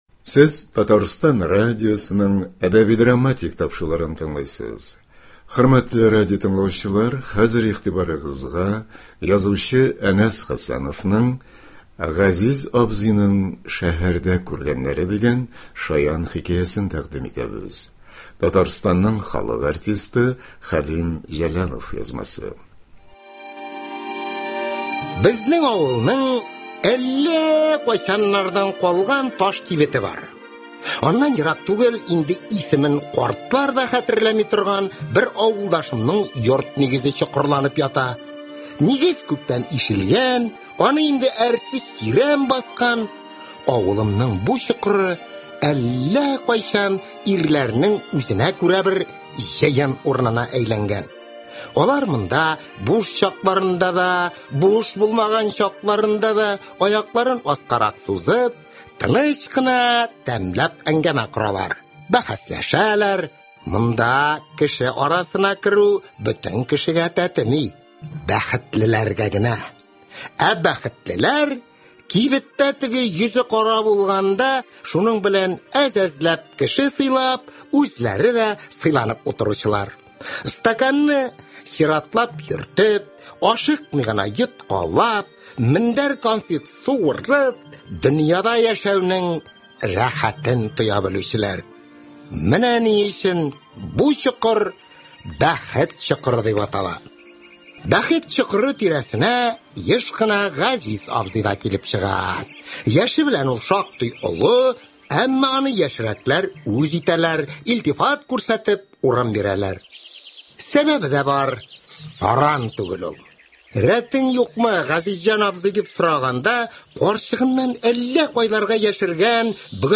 Хикәя.